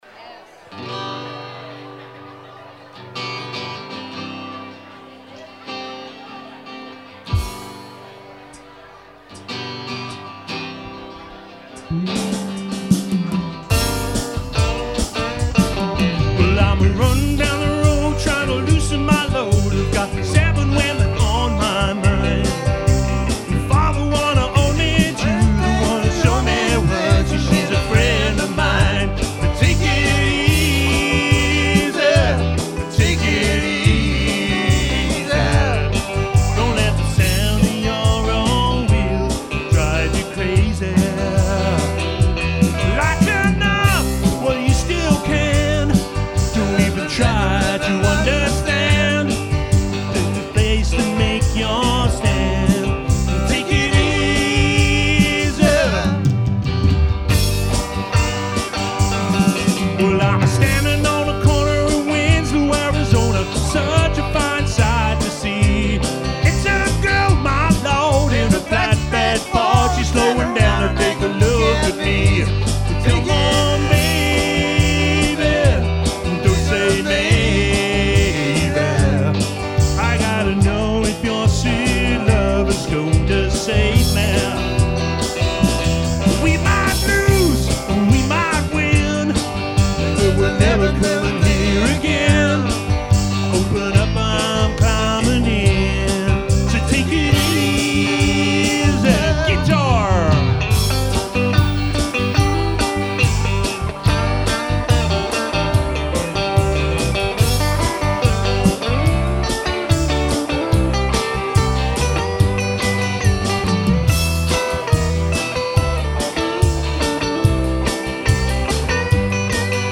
jazz, blues and dance tunes